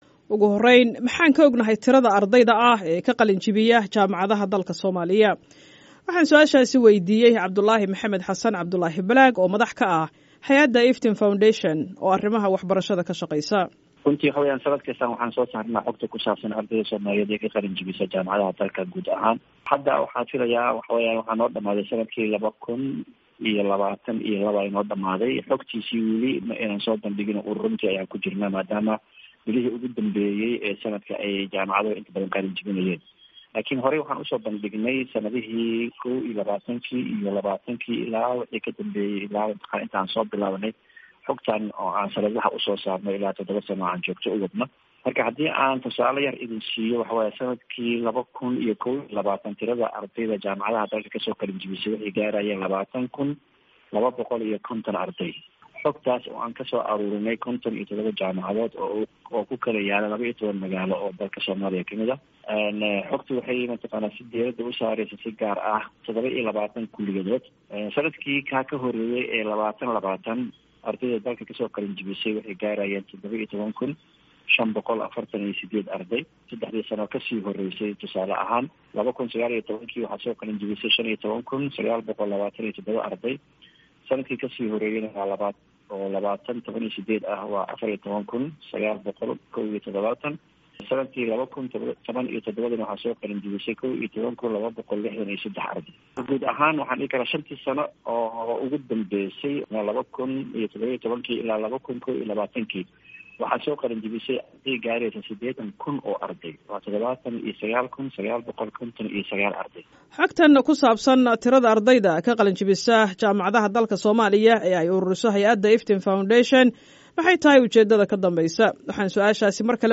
Warbixin: Heerka shaqa la'aanta ardayda ka baxday jaamacadaha